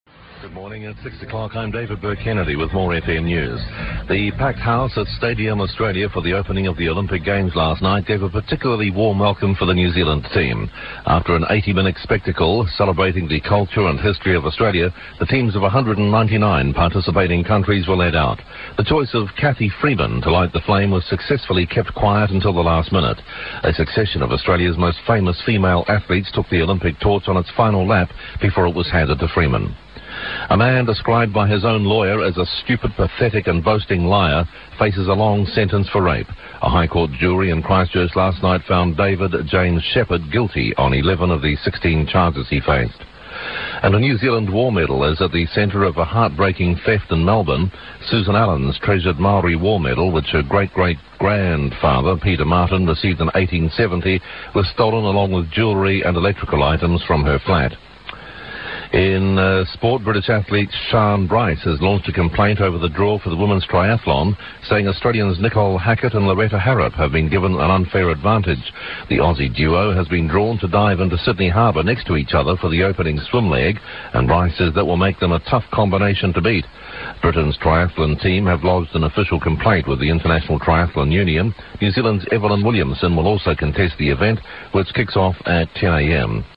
New Zealand English
The characteristics of New Zealand English are similar to southern British English and shows the same diphthong shift which is found in Australia. It is usually difficult to tell Australians and New Zealanders apart but the latter tend to have a schwa vowel in the KIT lexical set, i.e. kit is [kət]. They may also have a merger of pear and peer to [piə].
NewZealand_General.wav